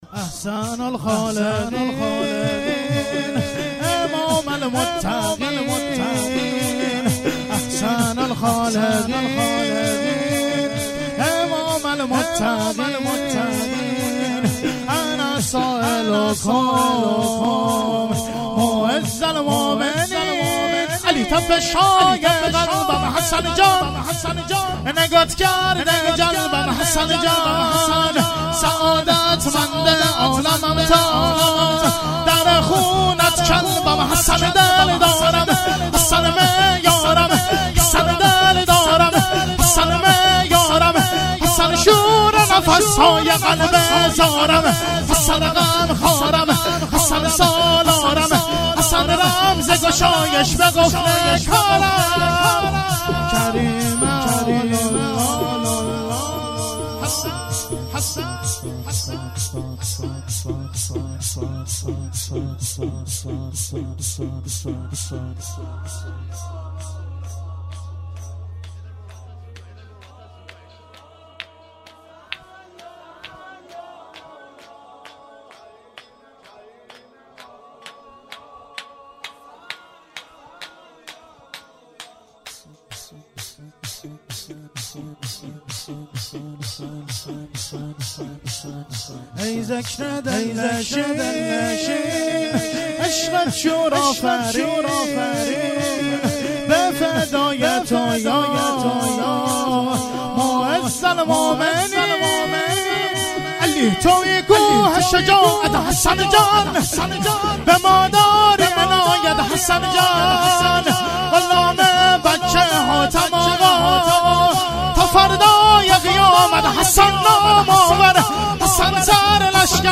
روضه هفتگی